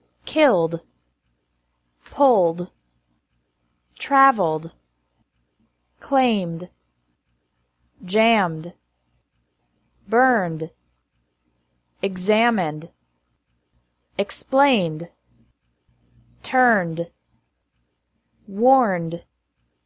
-ED pronounced like D
After regular verbs ending with an L / M / N sound